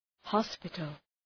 Προφορά
{‘hɒspıtəl}